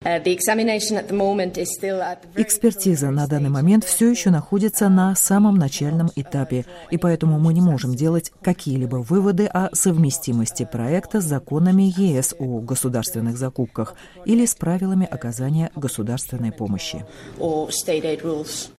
Об этом на пресс-конференции в Брюсселе